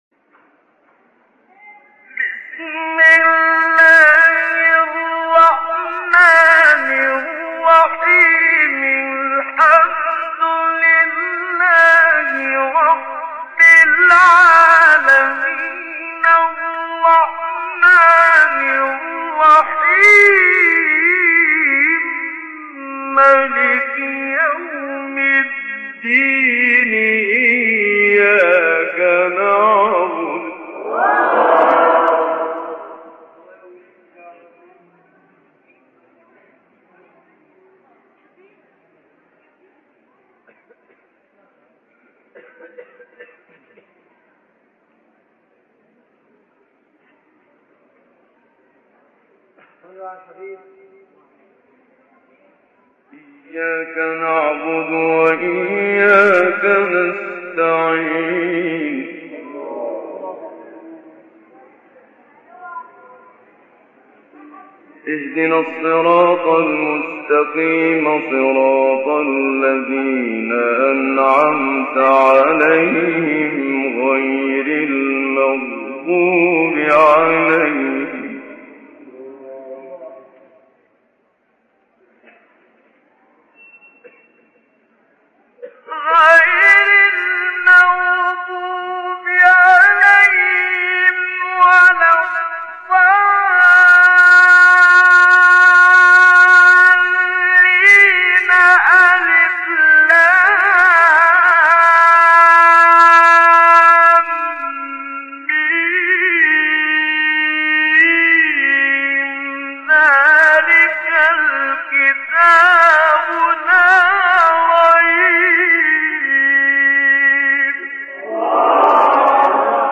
تلاوت سوره حمد و آیات ابتدایی سوره بقره استاد منشاوی | نغمات قرآن
سوره : حمد، بقره آیه : (1-7) * (1-2) استاد : محمد صدیق منشاوی مقام : مرکب خوانی( رست* سه گاه * رست) قبلی بعدی